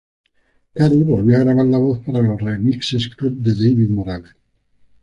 Pronúnciase como (IPA)
/ɡɾaˈbaɾ/